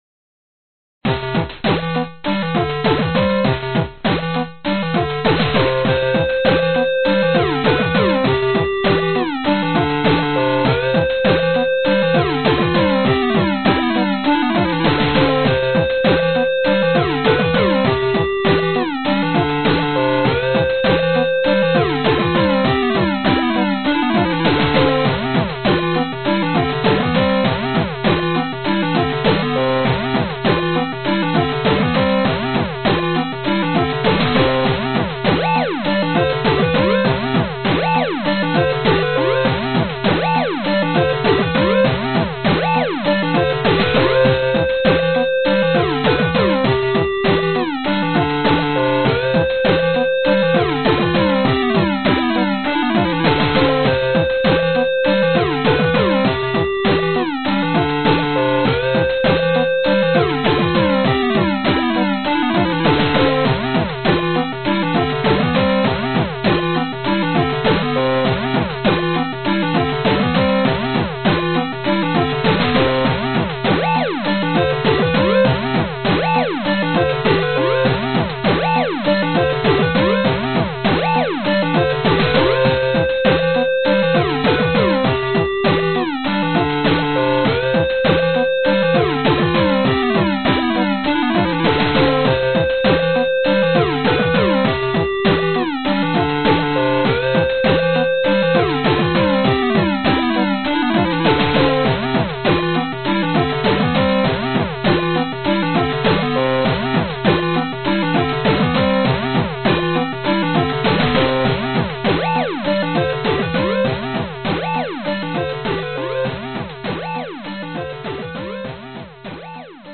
描述：一个简单快乐的小音乐。
Tag: chiptune 游戏音乐 合成器 跟踪器 循环 器乐